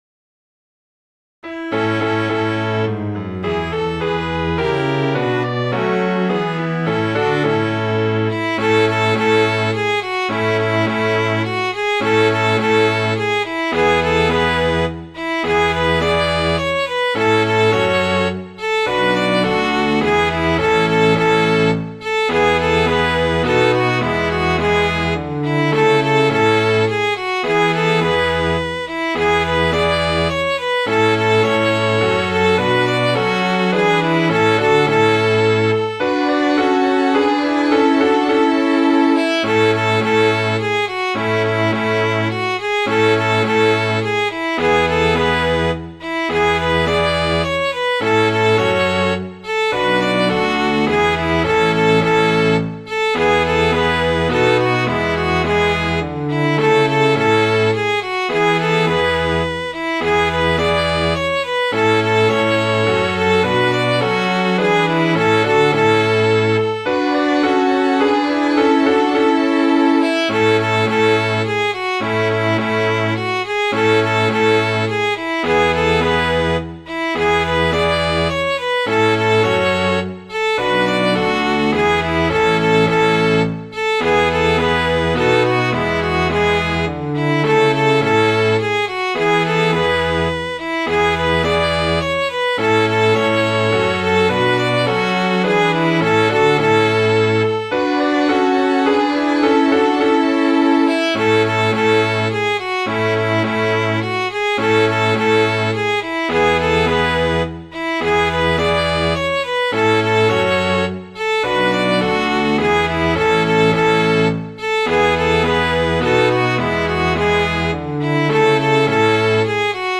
Midi File, Lyrics and Information to Flash Lad